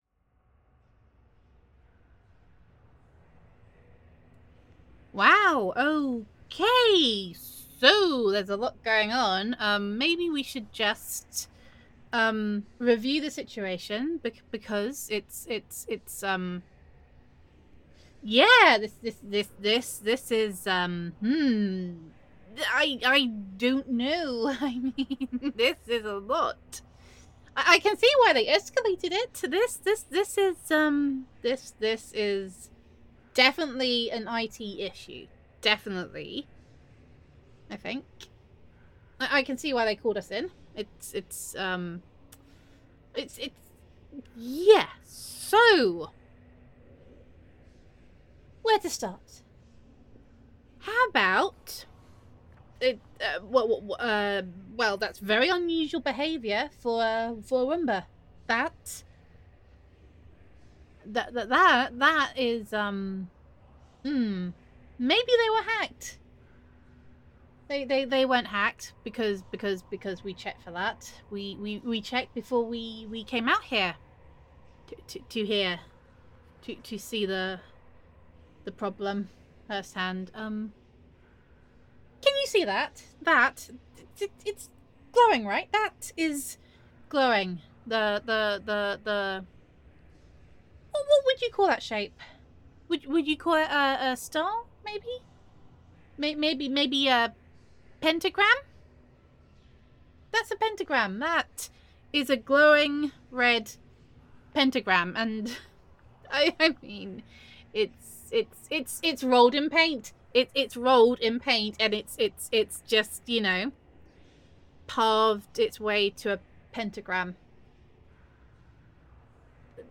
[F4A] Tier Four Tech Support [Tech Support Genius Roleplay][Troubleshooting][Supernatural][Roombas][Demon Summoning][Gender Neutral][When Your Roombas Are Acting Sinisterly Your First Call Is Tech Support]